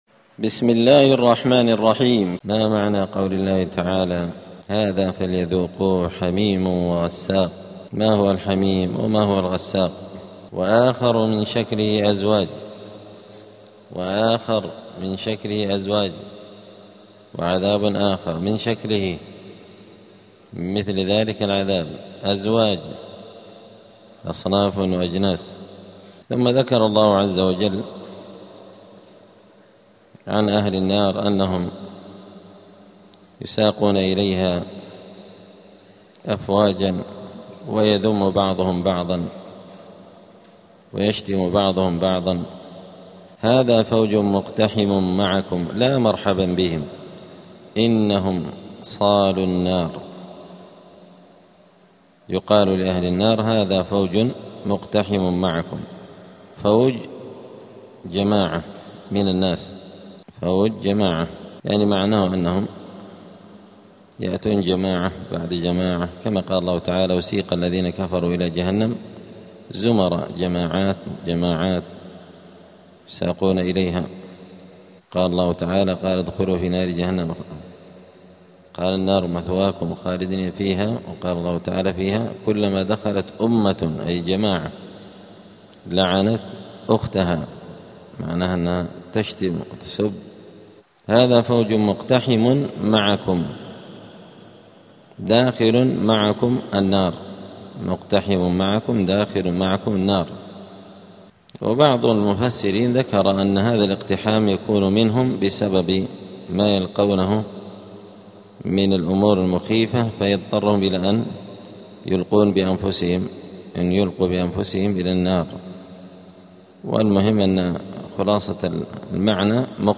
زبدة الأقوال في غريب كلام المتعال الدرس السادس بعد الثلاثمائة (306)